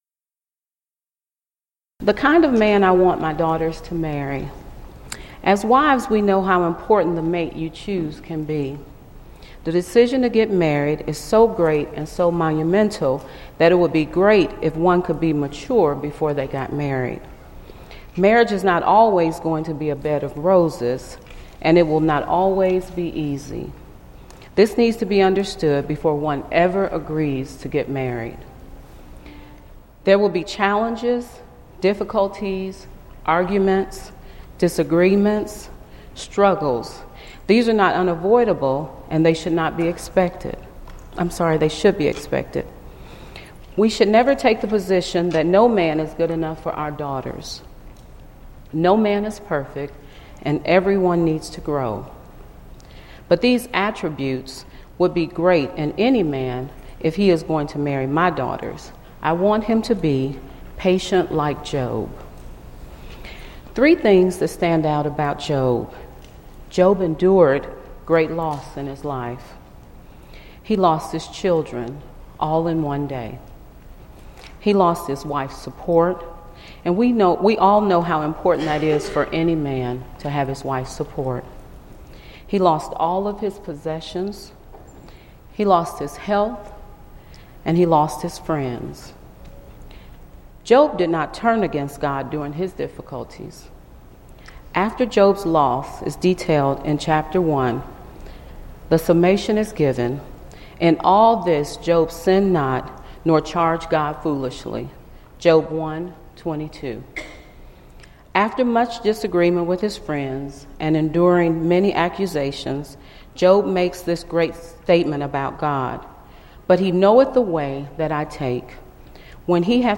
Event: 2014 Focal Point
lecture